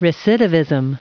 Prononciation du mot recidivism en anglais (fichier audio)
Prononciation du mot : recidivism